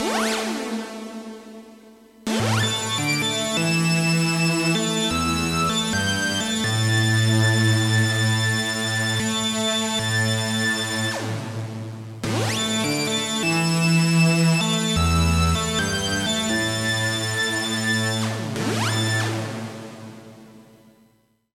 PitchADSR
PitchADSR.mp3